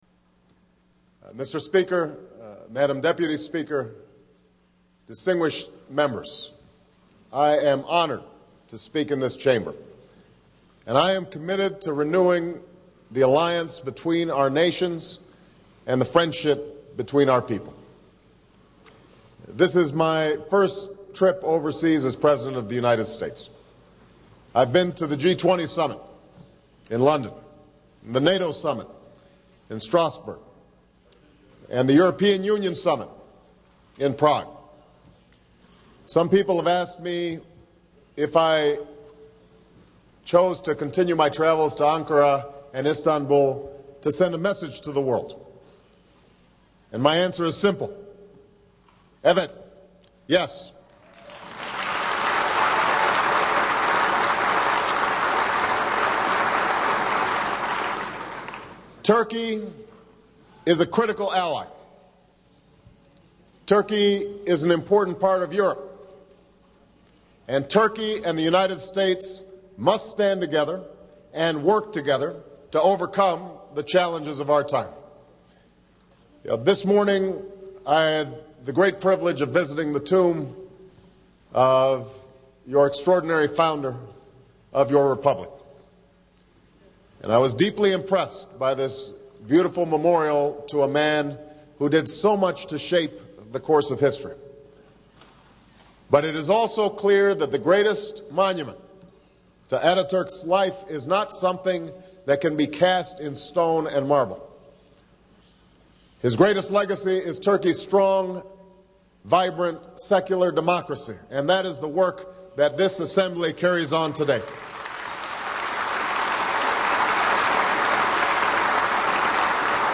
U.S. President Barack Obama speaks to the Grand National Assembly in Istanbul, Turkey
Broadcast on C-SPAN, Apr. 6, 2009.